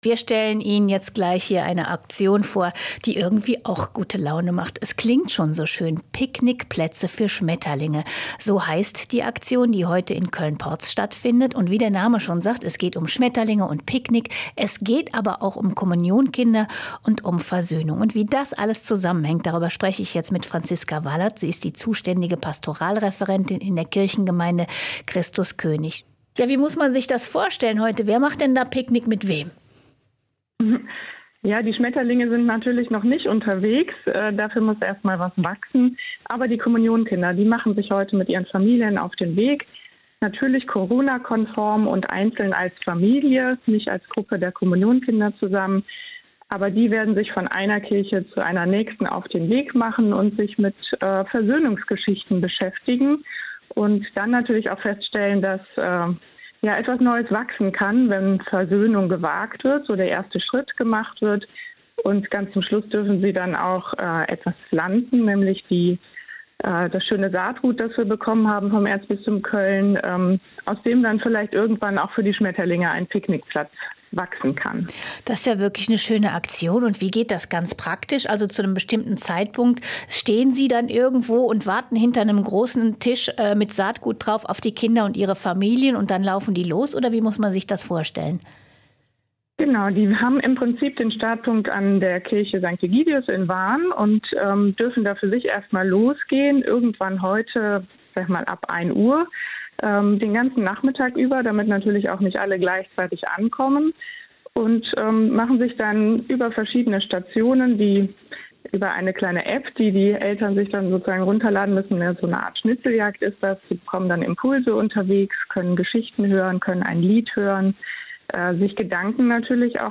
Ein Interview mit